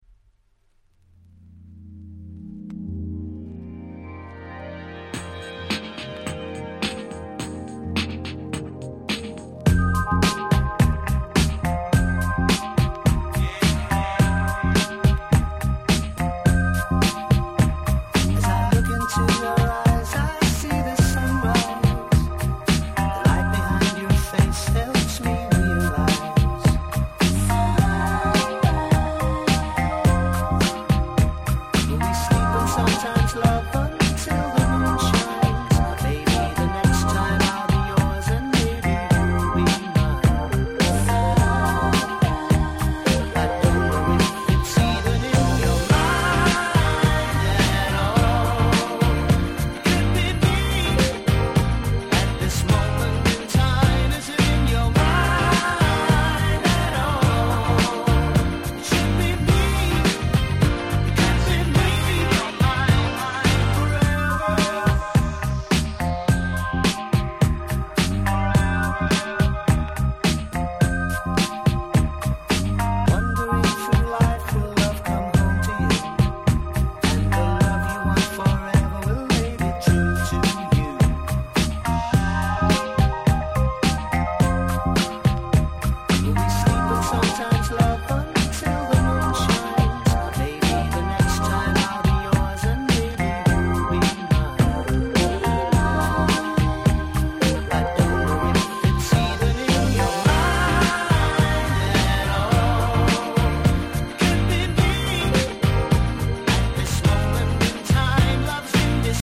03' Nice UK R&B !!